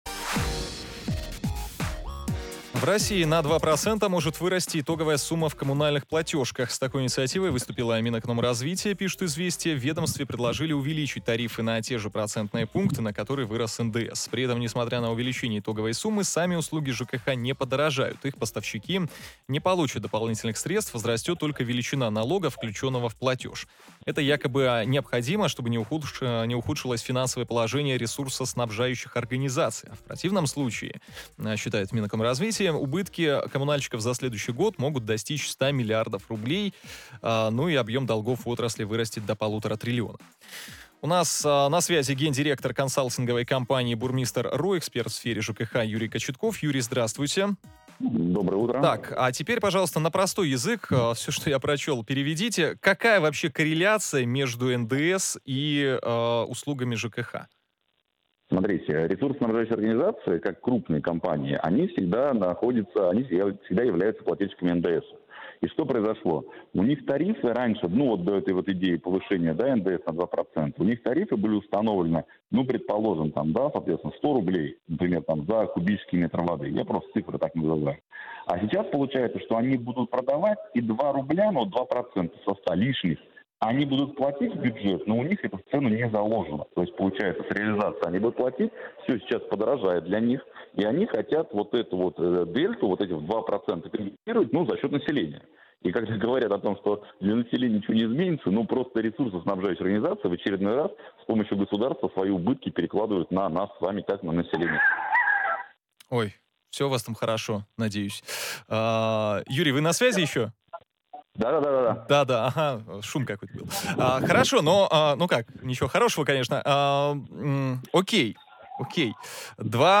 в прямом эфире радиостанции Москва-FM